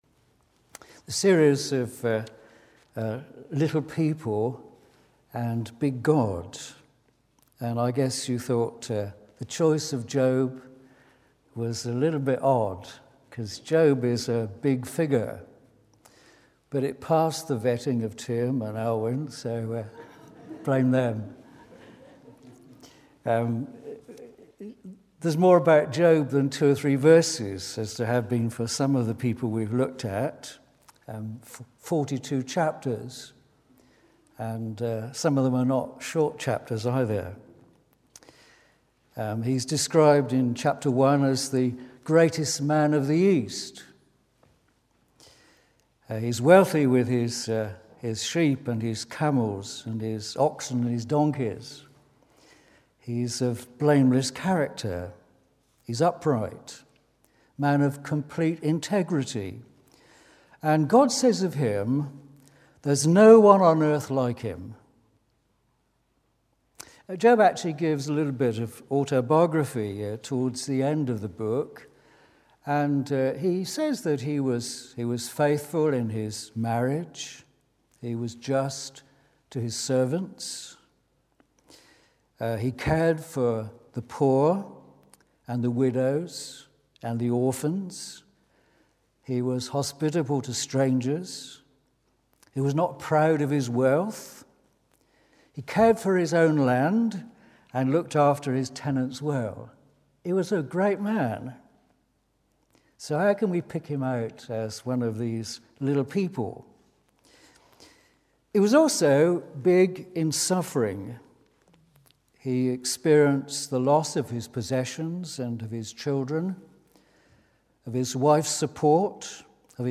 Bible Text: Job 42:1-17 | Preacher